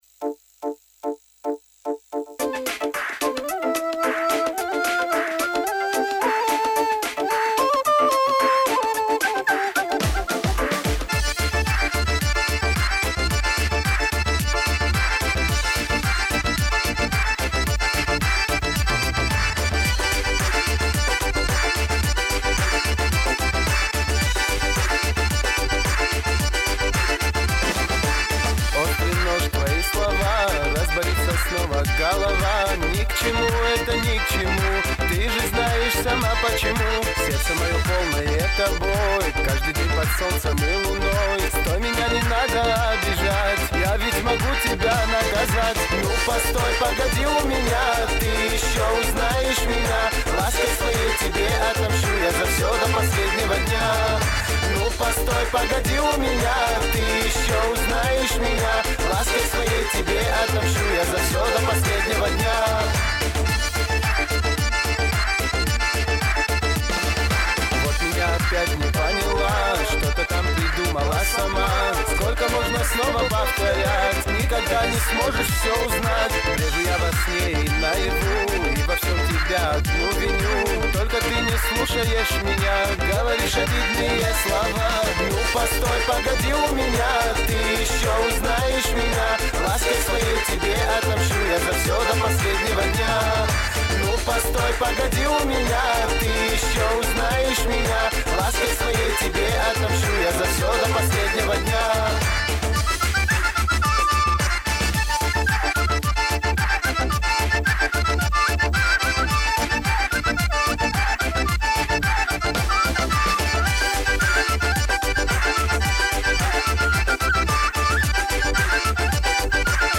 Категория: Восточная музыка » Кавказские песни